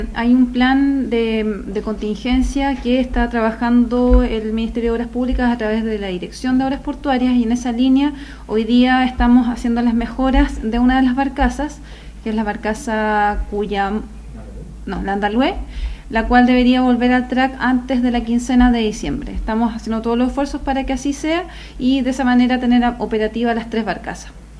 La seremi de Obras Públicas de Los Ríos, Nuvia Peralta, afirmó que el plan de contingencia de la Dirección de Obras Portuarias es tener a estas tres barcazas operativas antes de la quincena de diciembre.